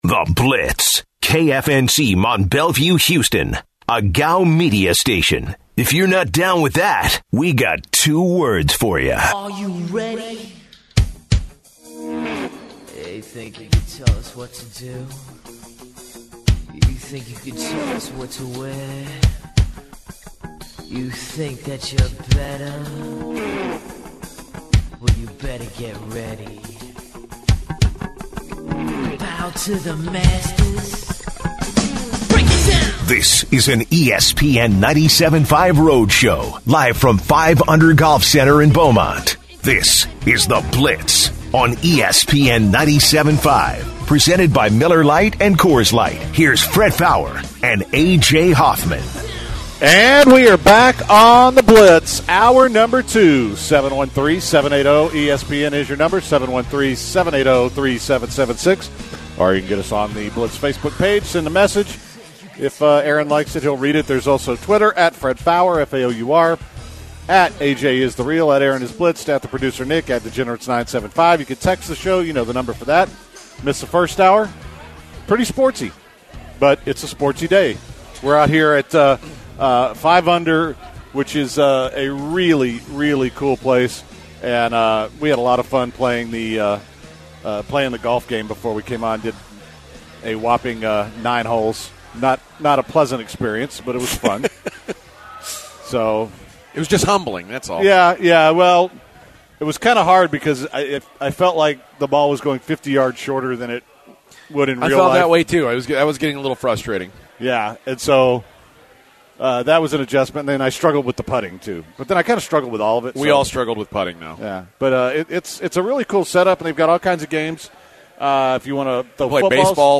broadcast live from 5 Under Golf in Beaumont! They look at the NFL lines for the weekend; talk about the Astros trading away Jake Marisnick;